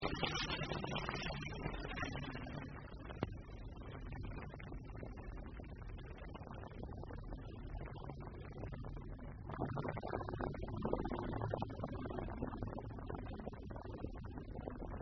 18 - Closing Jet Sound.mp3